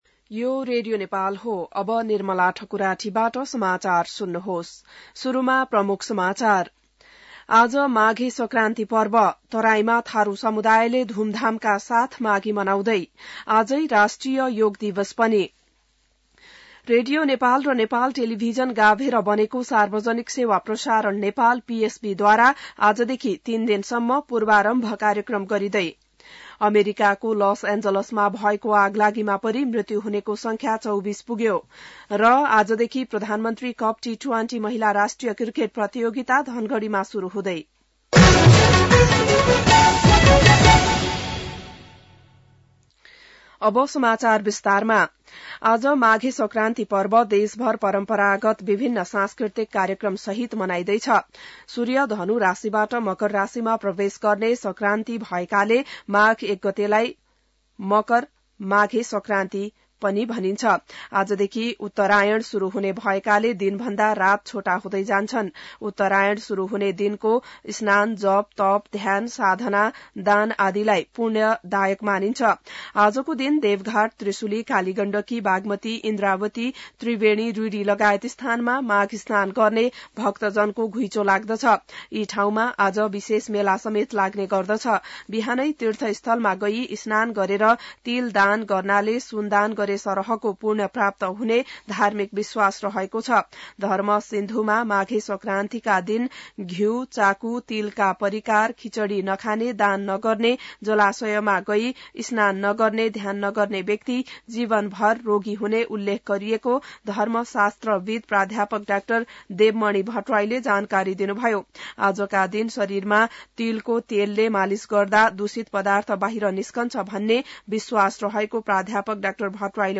बिहान ९ बजेको नेपाली समाचार : २ माघ , २०८१